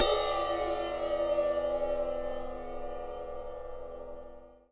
• Drum Ride Sample D Key 03.wav
Royality free ride sample tuned to the D note. Loudest frequency: 6119Hz
drum-ride-sample-d-key-03-WPA.wav